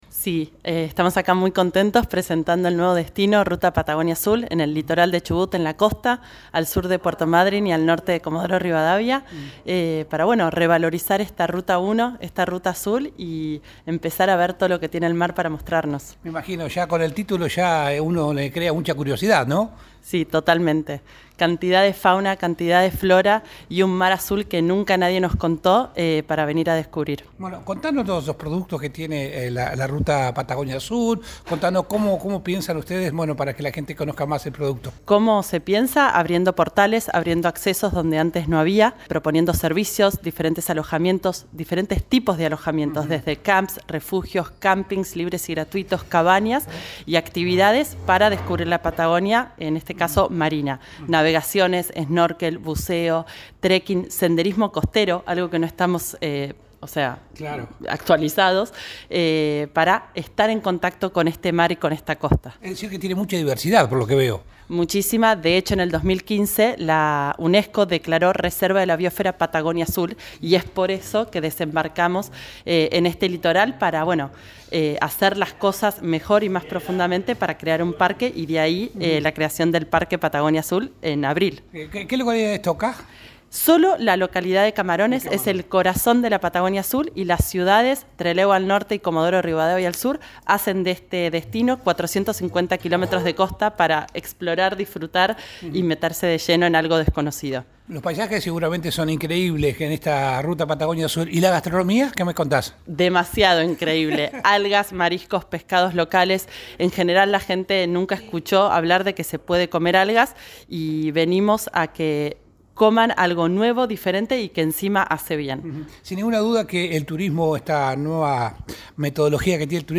en AM 590 Radio Continental